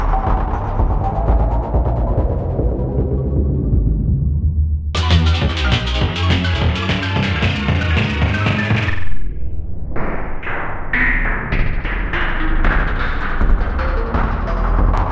fractured reverse and pitched speedy fx.ogg
Original creative-commons licensed sounds for DJ's and music producers, recorded with high quality studio microphones.